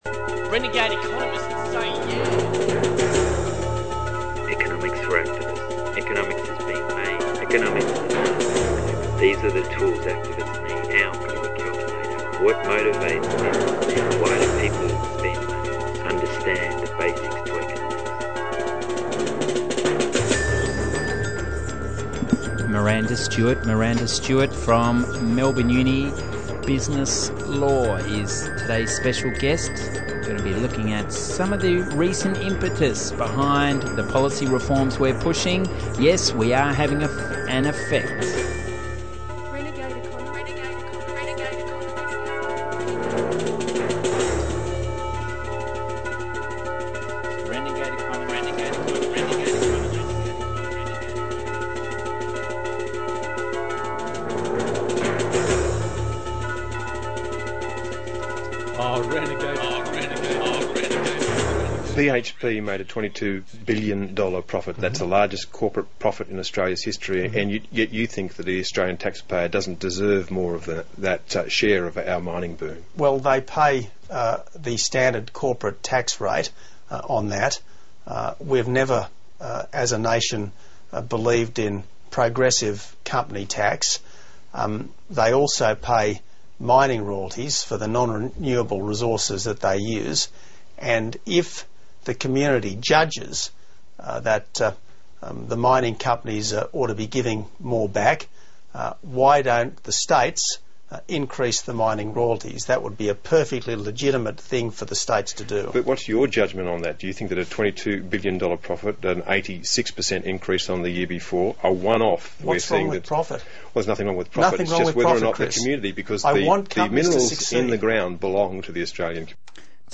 Michael Hudson was recently interviewed for our 200th show, following his visit to President Medvedev’s Global Policy Forum.